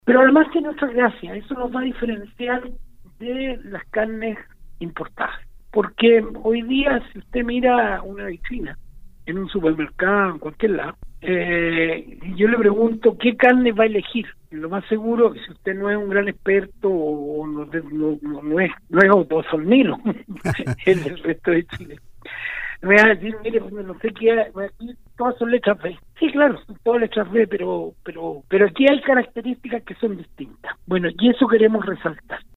El Director Nacional del SAG, Horacio Bórquez, en conversación con “Campo al Día” de Radio SAGO, dijo que las encuestas los llevaron a priorizar las carnes con marmoreo, con grasa blanca y segundo las magras.